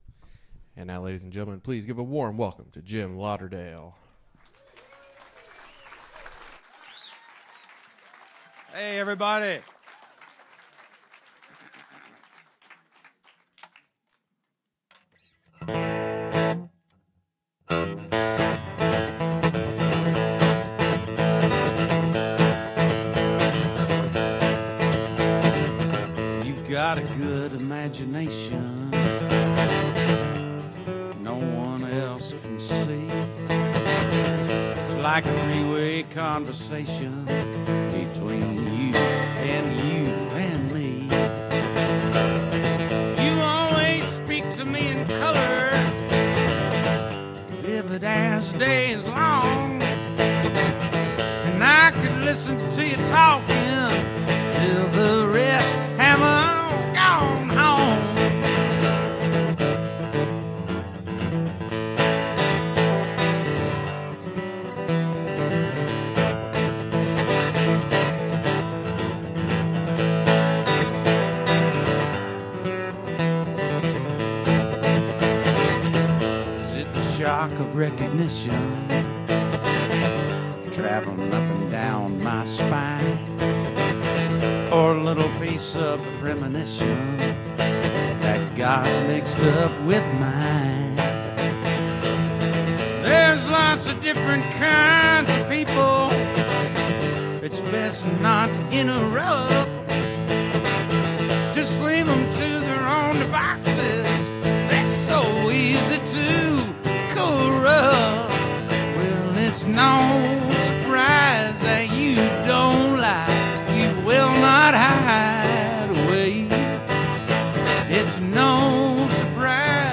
Sellersville Theater Sellersville, PA
Both shows are exceptional soundboard recordings.